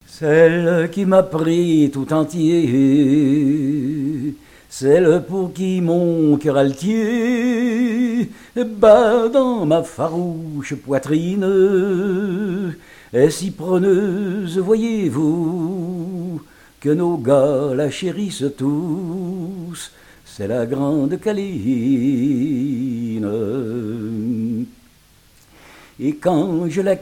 Genre strophique
chansons dont de marins
Pièce musicale inédite